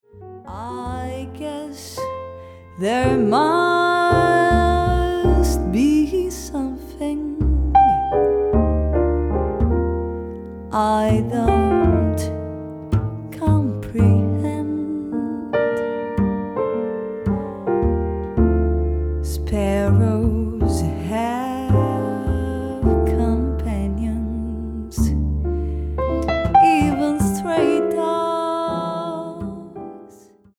vocals
guitar
piano/Fender Rhodes
bass
drums